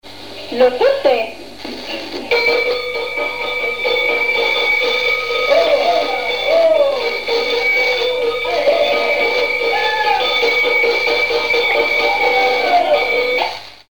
Mots Clé cloche(s), clochette(s)
Seytroux ( Plus d'informations sur Wikipedia ) Haute-Savoie
Catégorie Pièce musicale inédite